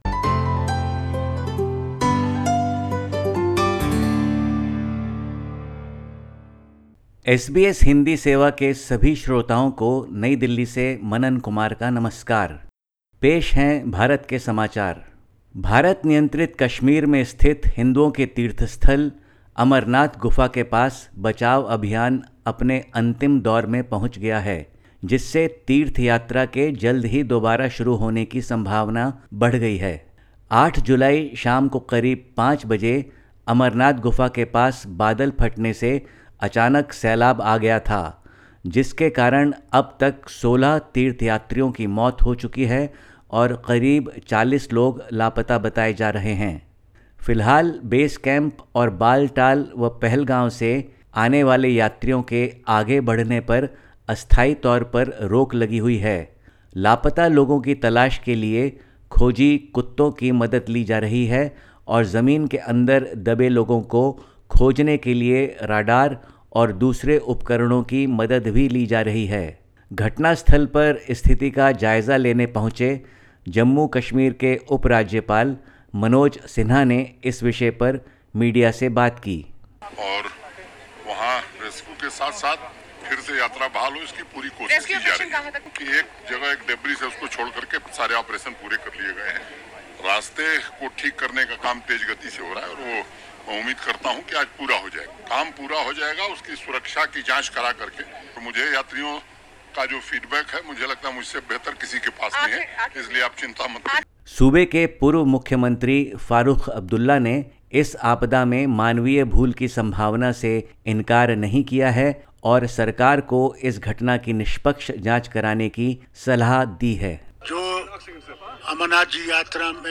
Listen to the latest SBS Hindi report from India. 11/07/2022